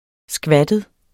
Udtale [ ˈsgvadəð ]